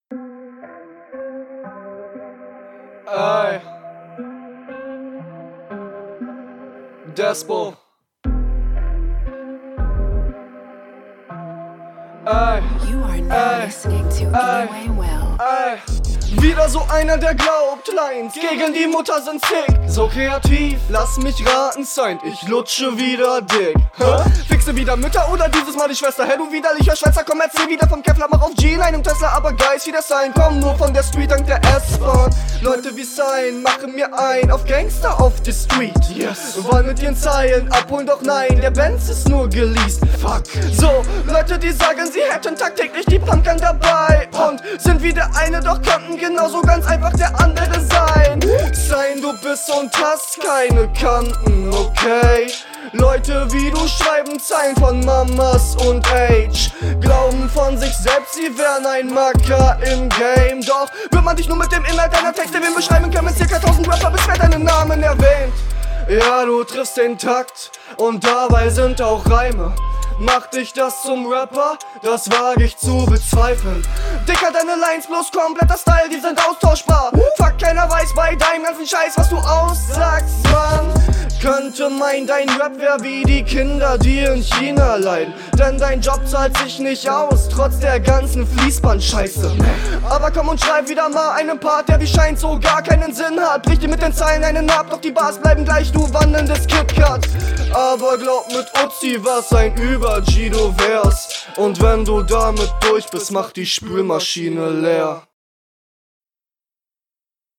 Gute Beatwahl für deine Stimmfarbe!
Flow ziemlich gut, auch die Betonungen variieren, wobei ich deine Stimme trotzdem nicht mag, was …